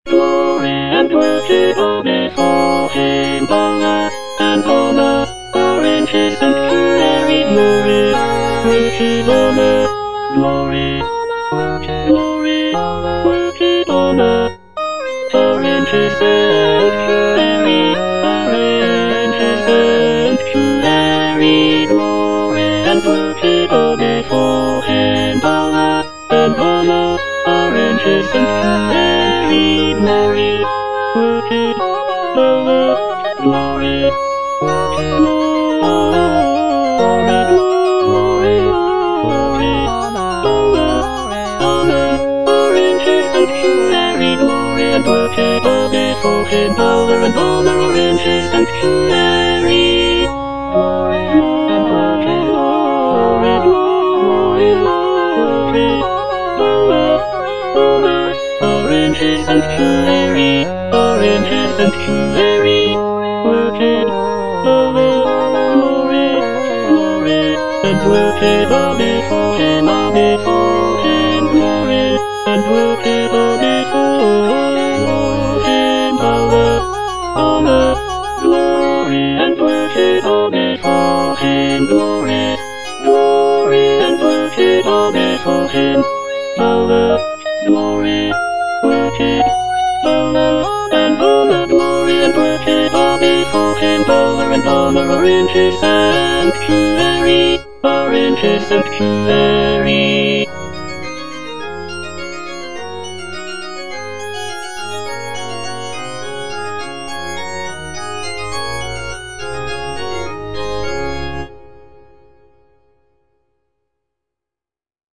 G.F. HÄNDEL - O COME, LET US SING UNTO THE LORD - CHANDOS ANTHEM NO.8 HWV253 (A = 415 Hz) Glory and worship are before him - Tenor (Emphasised voice and other voices) Ads stop: auto-stop